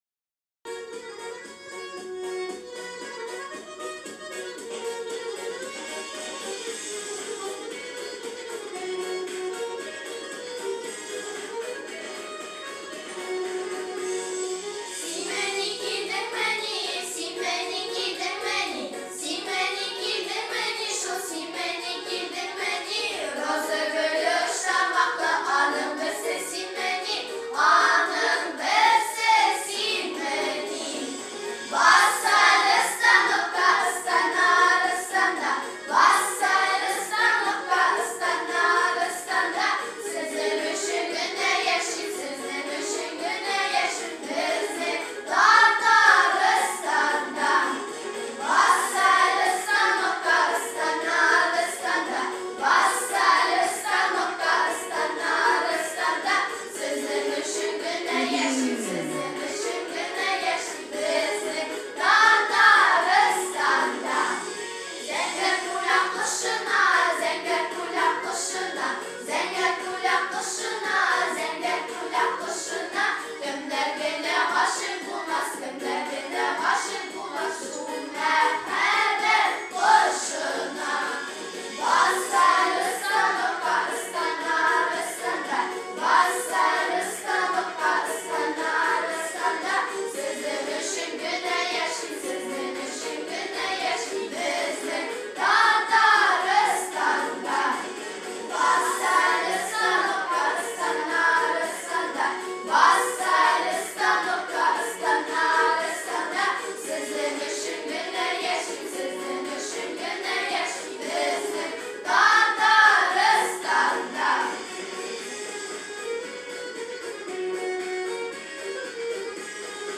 Песня в исполнении фольклорного ансамбля